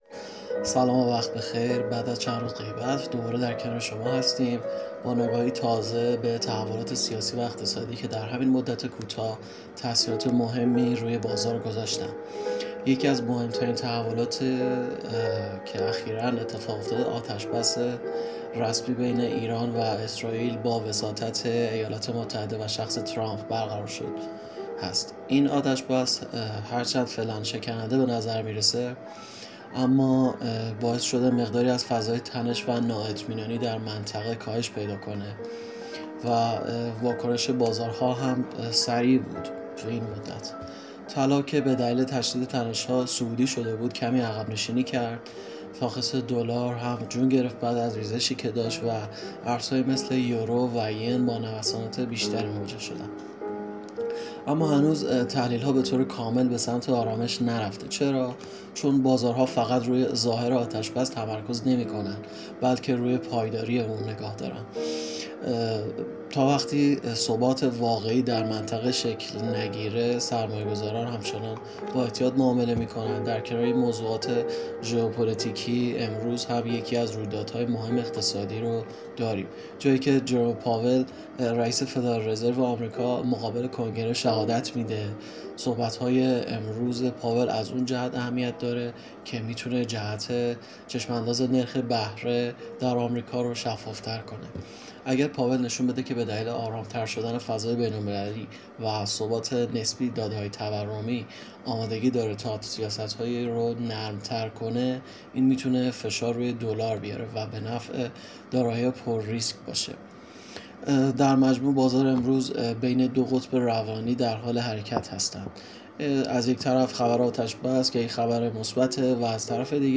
🔸گروه مالی و تحلیلی ایگل با تحلیل‌های صوتی روزانه در خدمت شماست! هدف اصلی این بخش، ارائه تحلیلی جامع و دقیق از مهم‌ترین اخبار اقتصادی و تأثیرات آن‌ها بر بازارهای مالی است.